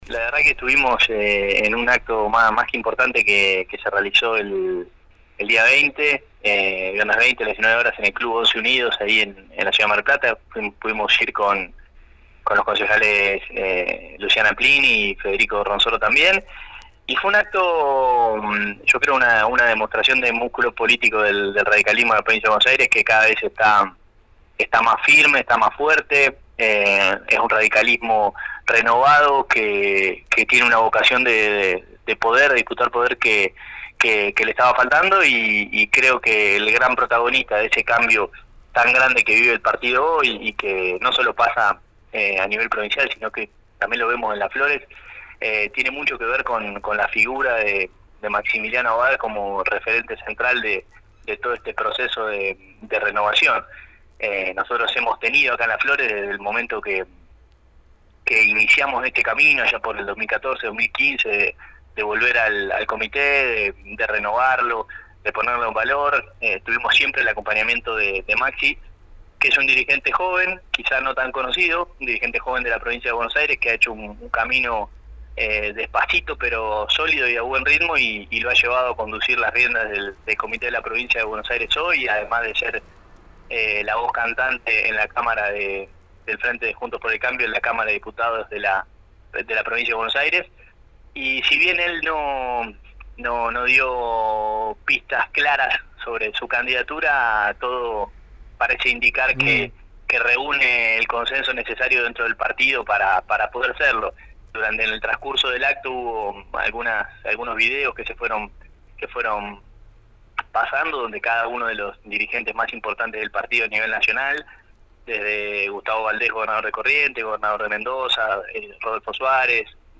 En diálogo con la mañana de la 91.5, Gennuso afirmó que «vivimos una jornada muy importante donde se vio un radicalismo unido y renovado.